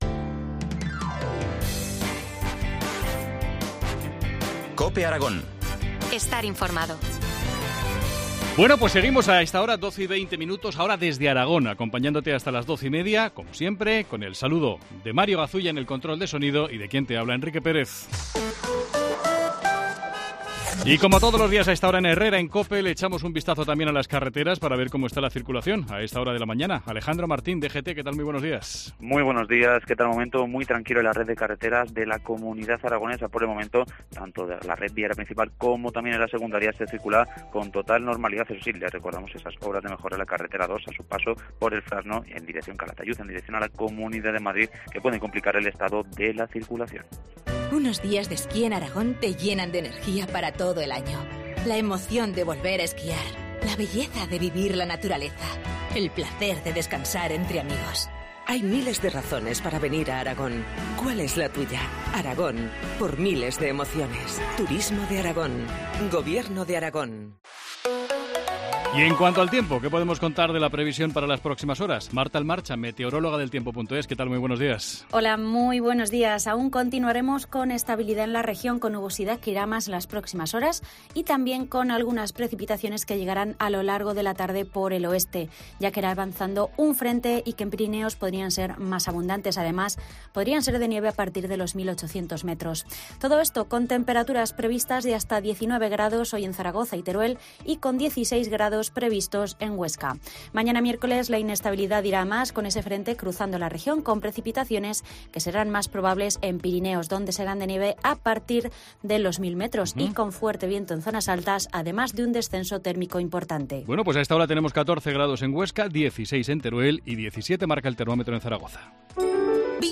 AUDIO: Entrevista del día en COPE Aragón